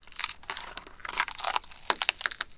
MONSTERS_CREATURES
ALIEN_Insect_03_mono.wav